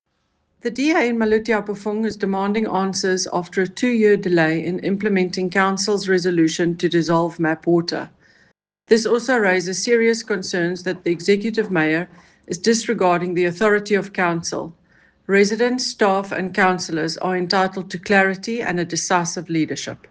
English and Afrikaans soundbites by Cllr Eleanor Quinta and Sesotho soundbite by Cllr Tlalane Motaung